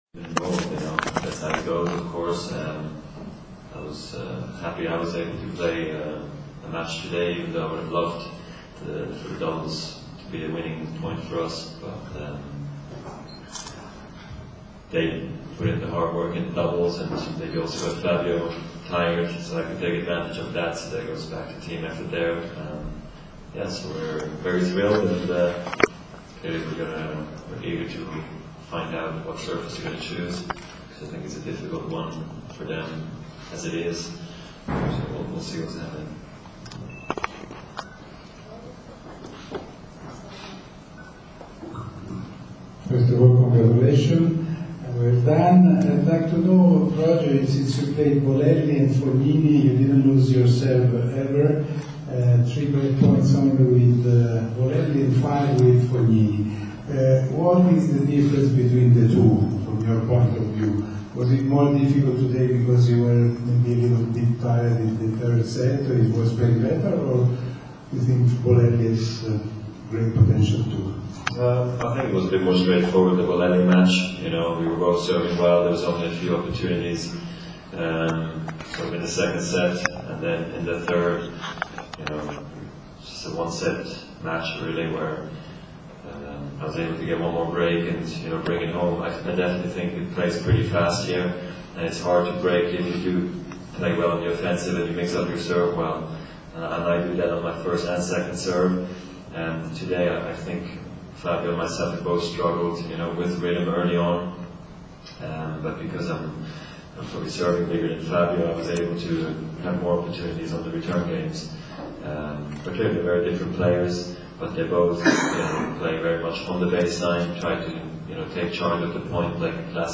La squadra svizzera dopo la vittoria con l’Italia (in inglese)